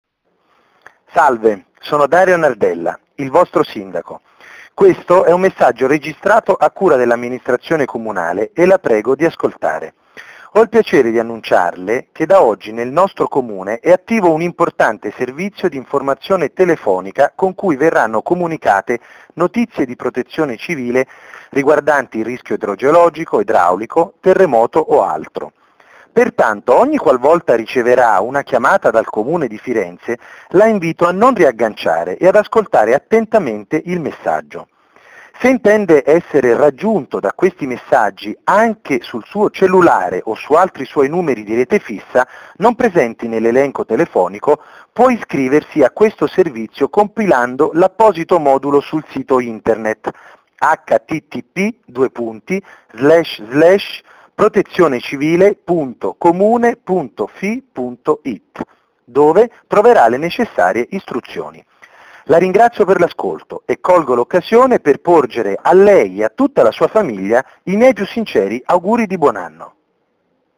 E proprio il sindaco oggi ha registrato il primo messaggio che arriverà nelle oltre 80mila utenze telefoniche fisse di Firenze. Un messaggio di presentazione del servizio, che vuole informare di questa nuova opportunità di avviso in caso di emergenza e che contiene anche informazioni utili per chi volesse anche essere raggiunto sul telefono cellulare.